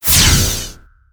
alien_bellow_02.ogg